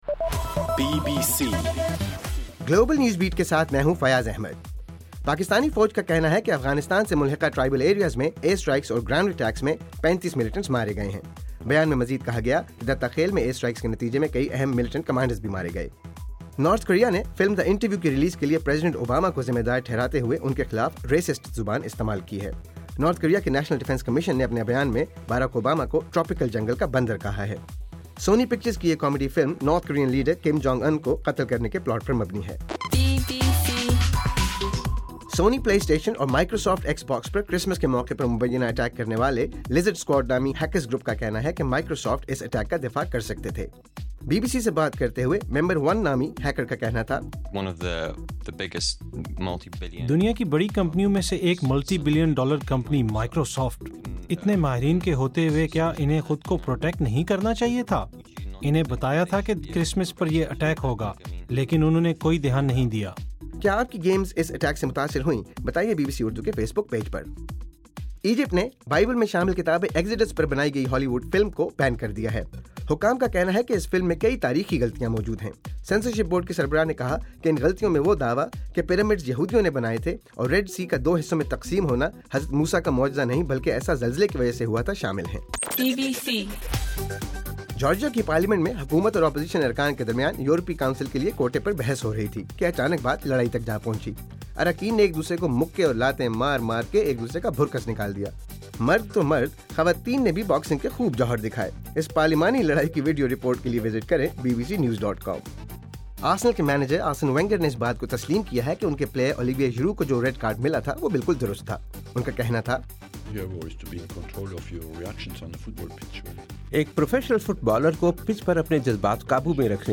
دسمبر 27: رات 8 بجے کا گلوبل نیوز بیٹ بُلیٹن